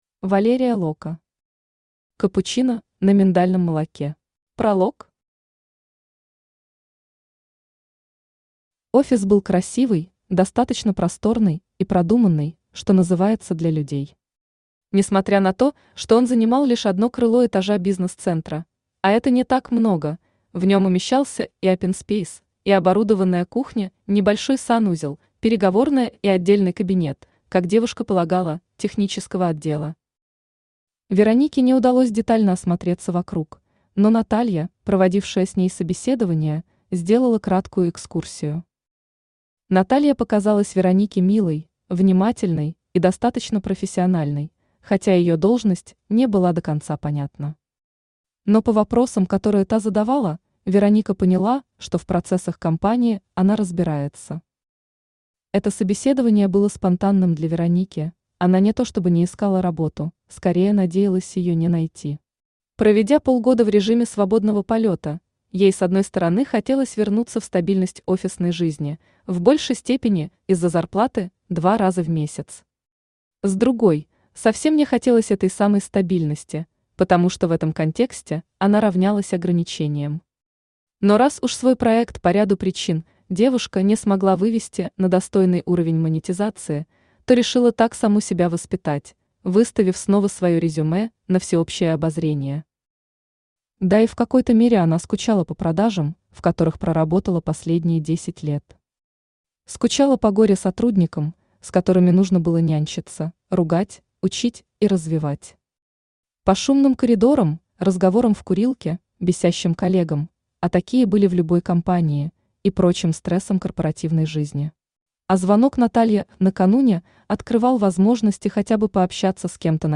Аудиокнига Капучино на миндальном молоке | Библиотека аудиокниг
Aудиокнига Капучино на миндальном молоке Автор Валерия Локка Читает аудиокнигу Авточтец ЛитРес.